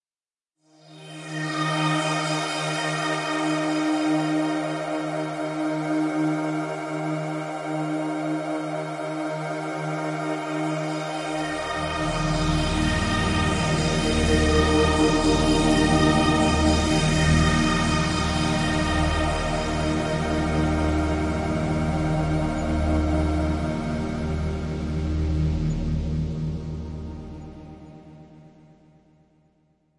描述：使用vst乐器制作的科幻声音/无需归属 公共领域
Tag: 未来 无人机 驱动器 背景 隆隆声 黑暗 冲动 效果 FX 急诊室 悬停 发动机 飞船 氛围 完善的设计 未来 空间 科幻 电子 音景 环境 噪音 能源 飞船 大气